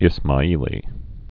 (ĭsmä-ēlē) also Is·ma·i·li·an (-ēlē-ən)